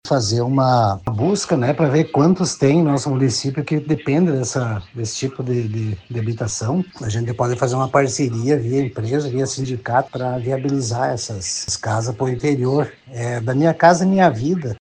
O prefeito, Alzevir de Marchi, ressalta que nesse momento o objetivo é realizar levantamento da demanda. (Abaixo, sonora de Alzevir)